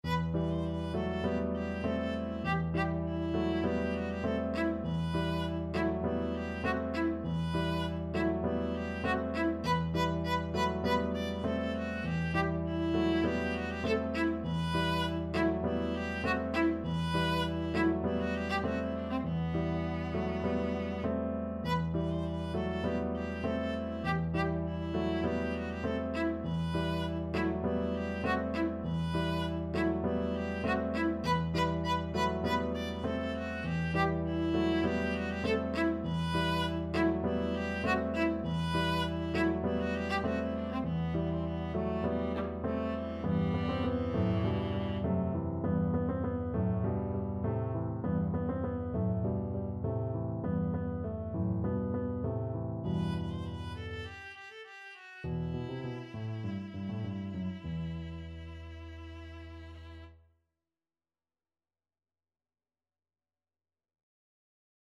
4/4 (View more 4/4 Music)
Allegro (View more music marked Allegro)
Classical (View more Classical Viola Music)